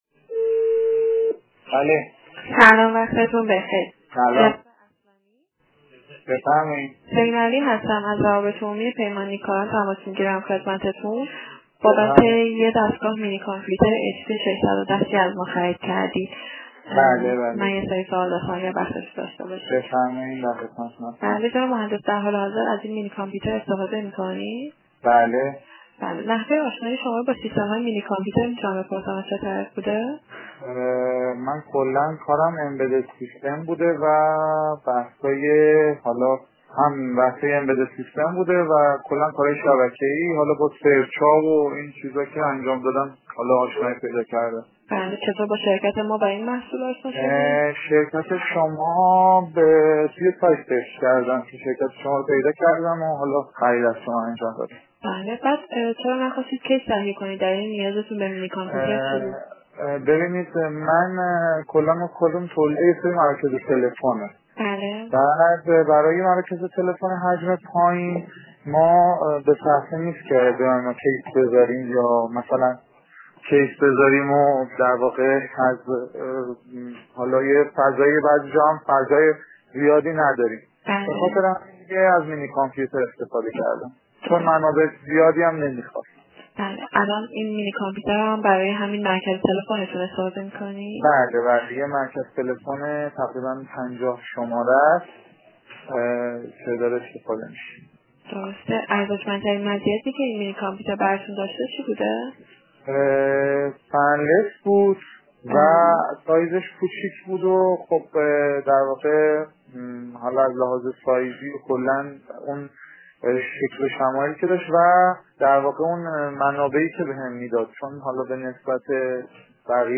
بدین منظور تعدادی مصاحبه با مشتریان عزیزمان که از مینی کامپیوتر استفاده کرده اند، گردآوری شده است.